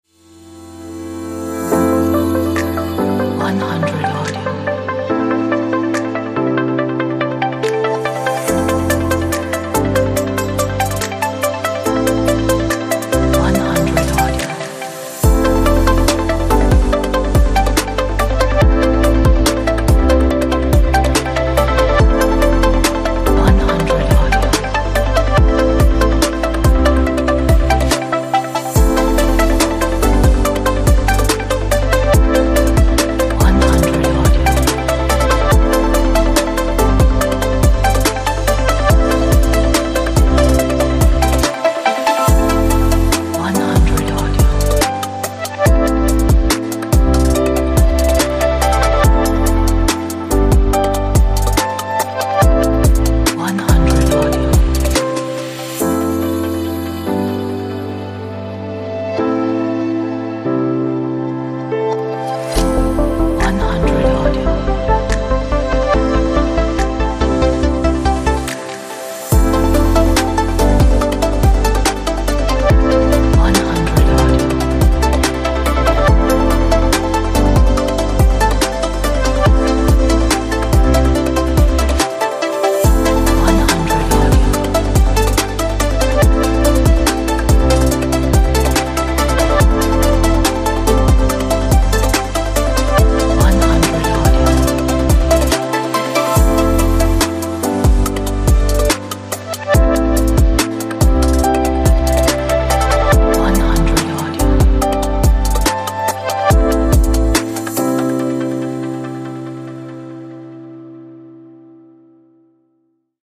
minimalistic and inspiring electronic track